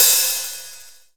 Crashes & Cymbals
Amsterdam Ride 2.wav